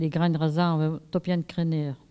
Châteauneuf ( Plus d'informations sur Wikipedia ) Vendée
collecte de locutions vernaculaires